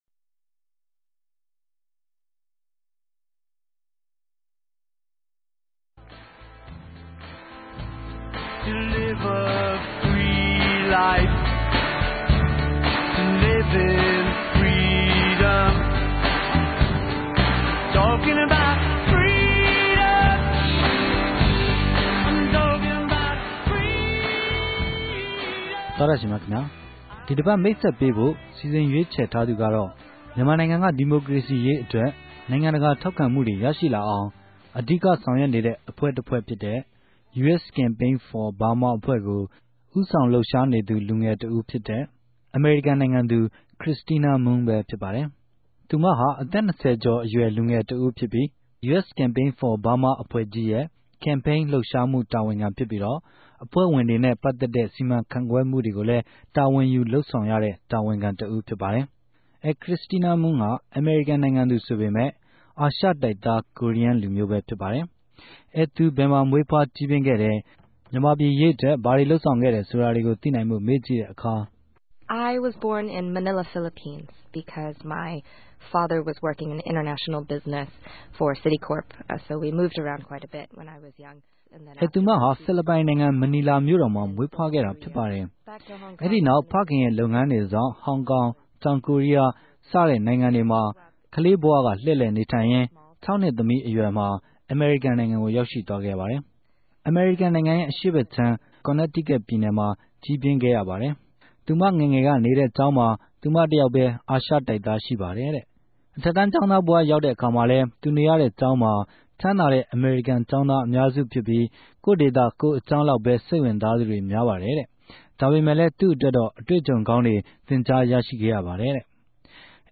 အဲ သူဘယ်မြာမြေးဖြားဋ္ဌကီးူပင်းခဲ့တယ်၊ ူမန်မာူပည်အရေး အတြက် ဘာတေလြုပ်ဆောင်ခဲ့တယ်ဆိုတာသိံိုင်ဖိုႛ RFA �ကတြေႚဆုံ မေးူမန်းခဲ့ပၝတယ်။